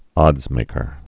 (ŏdzmākər)